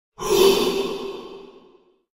Ghost sound Sound Button